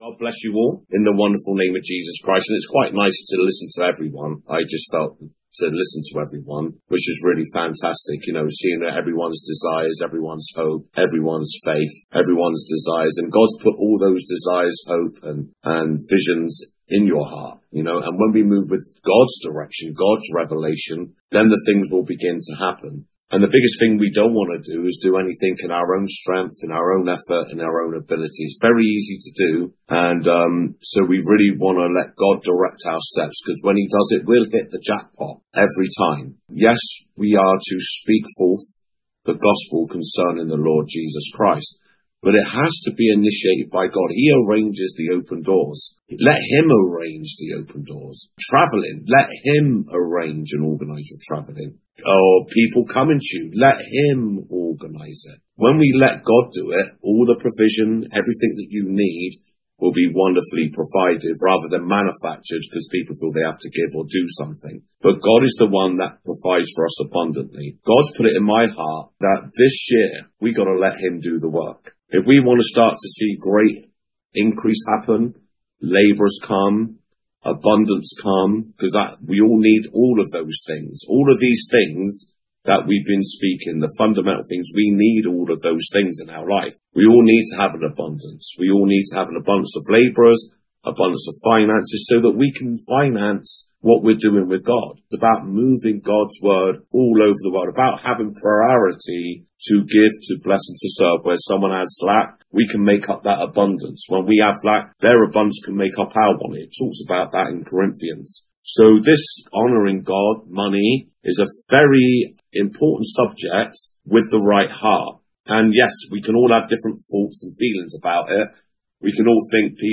The rest of the leaders meeting will be added very soon.